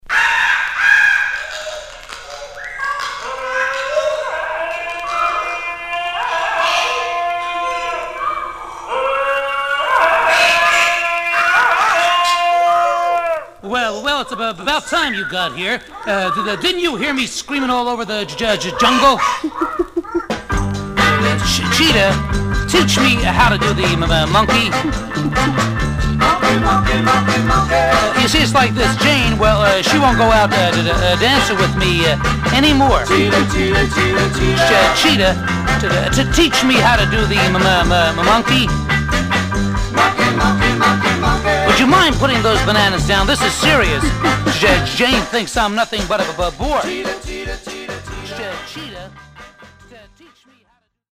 Novelty (Sm TOL) Condition: M-
Stereo/mono Mono